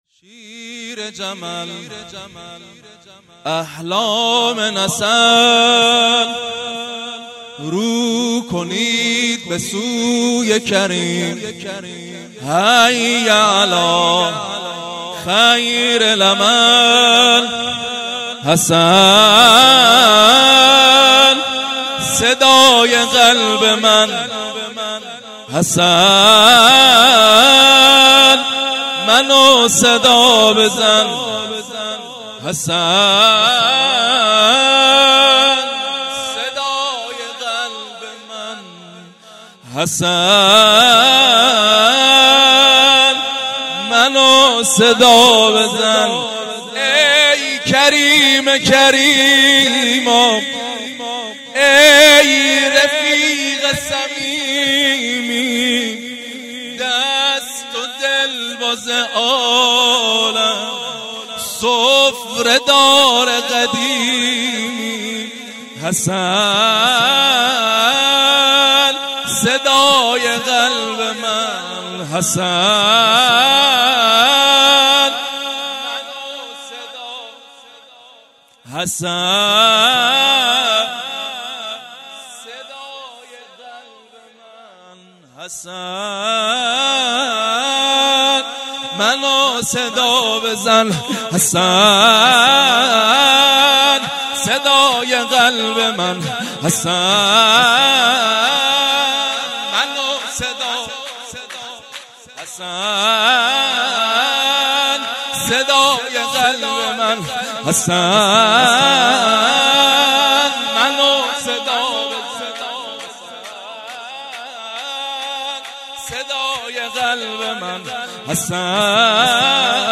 0 0 شور